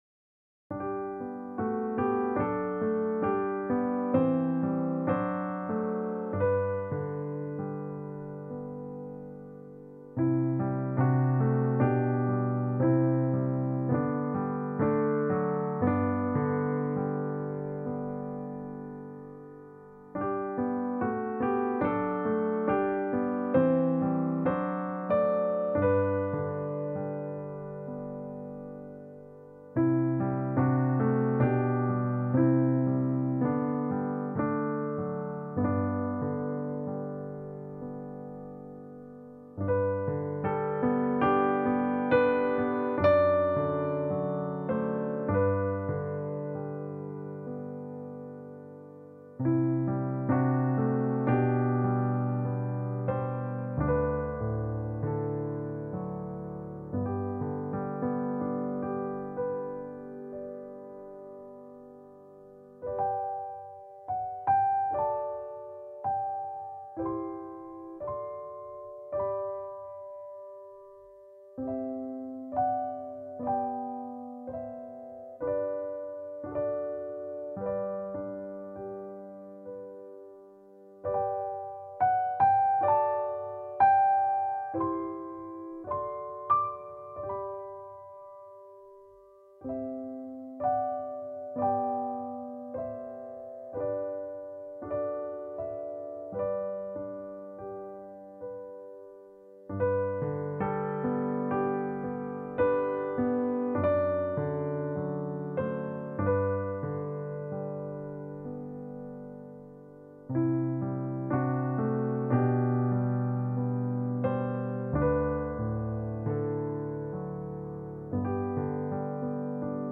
Voicing/Instrumentation: Piano Solo